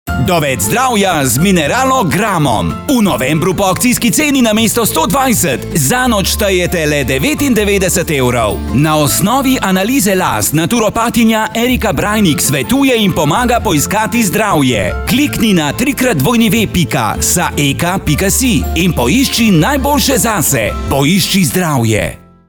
Reklama na Radiu Robin Mineralogram
Reklama-Saeka-mineralogram.mp3